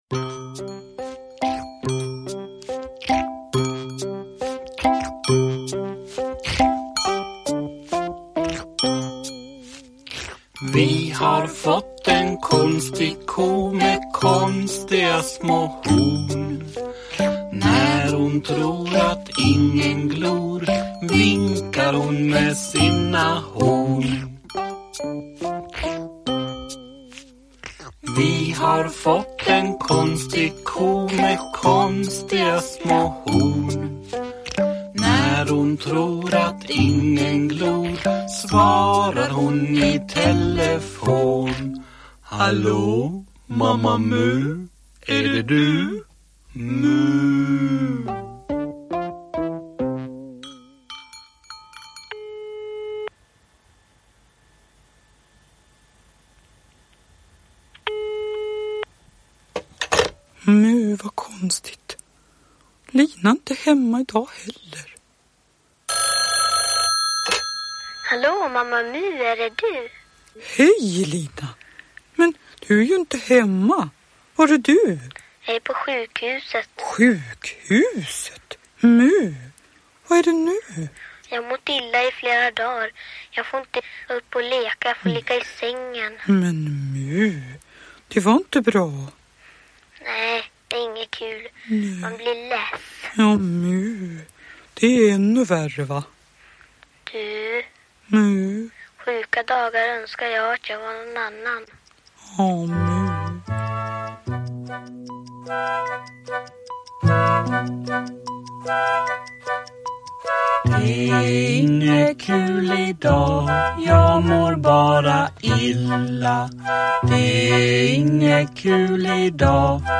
Dramatiserad berättelse med musik.
Uppläsare: Jujja Wieslander